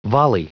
Prononciation du mot volley en anglais (fichier audio)